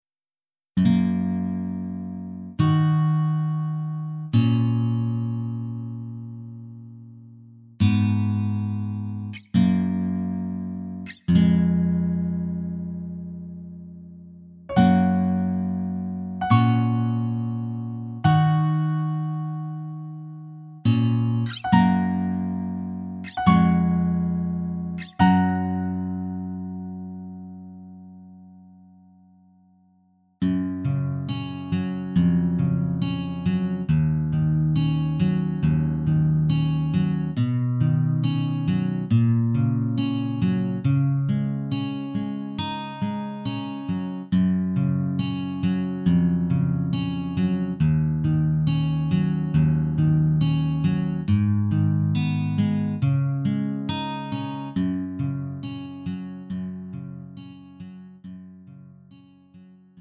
음정 원키 3:35
장르 가요 구분